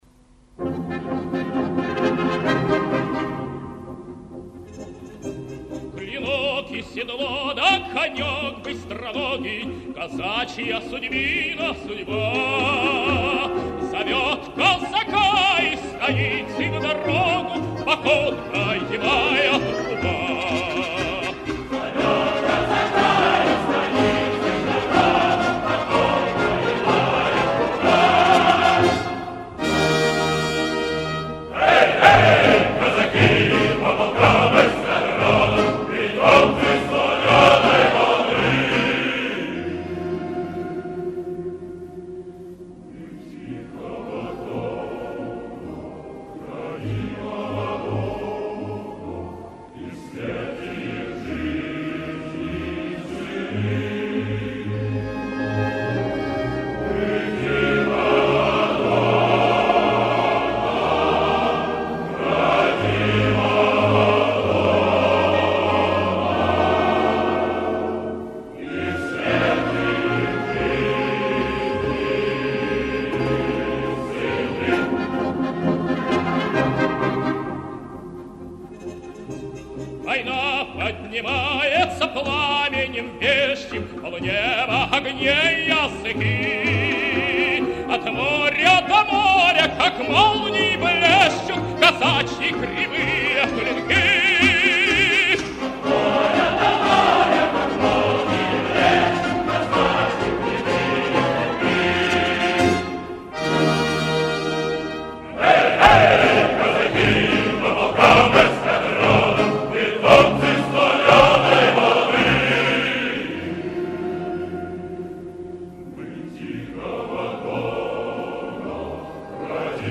Запись с концерт